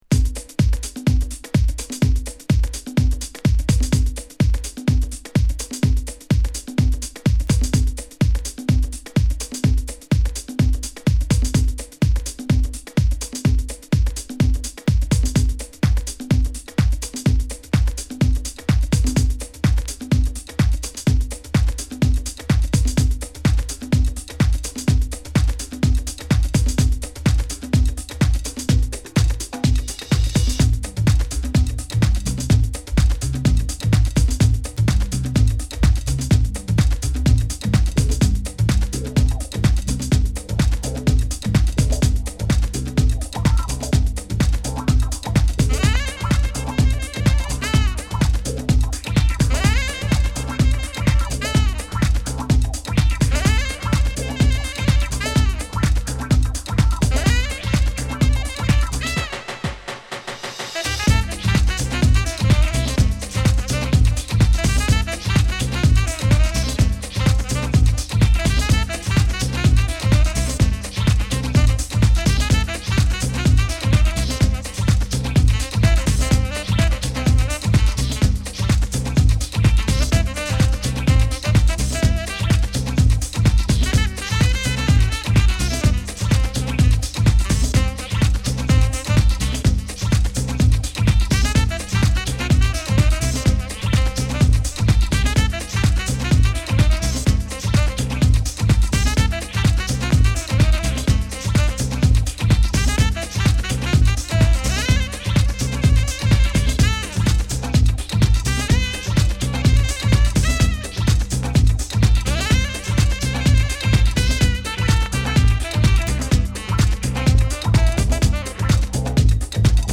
ジャッキンさを持ったビートに流麗なフルートが絡むジャジーハウス！！